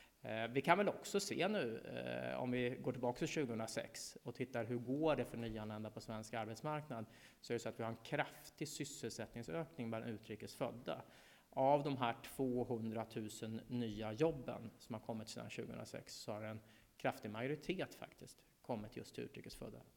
Vi kunde igår höra hur integrationsminister Myten hade presskonferens och informerade om att det nu ska ställas samma krav på utrikesfödda som på svenskar.
Lyssna särskilt på denna floskel som Myten stolt och röd i ansiktet förkunnande.